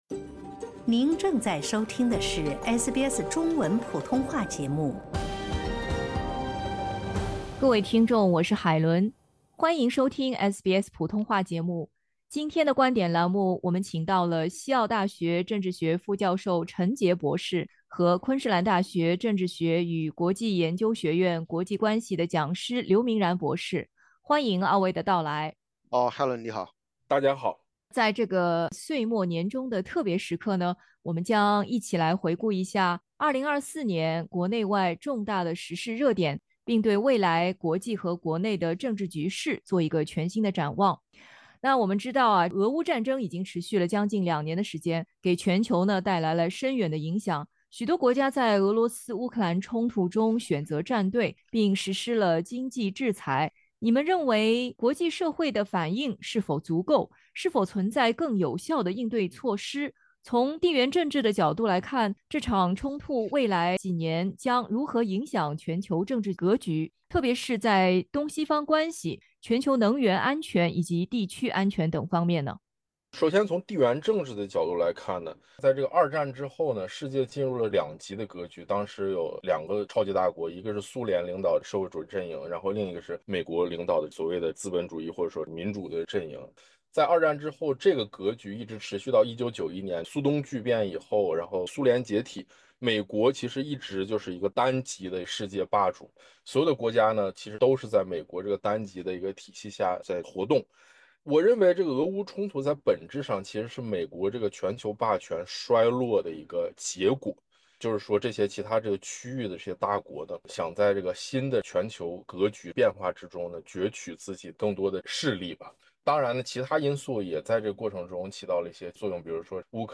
阿萨德垮台后的叙利亚如何牵动国际社会、处于持续冲突中的以色列、俄乌经年的战争、特朗普的贸易战、错综复杂的美中关系如何影响世界格局......持续进行的政治博弈与权力较量将在2025的国际舞台如何上演？如点击音频收听采访。